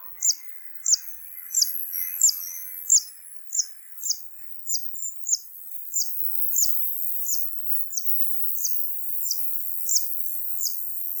graszanger
🔭 Wetenschappelijk: Cisticola juncidis
graszanger_zang.mp3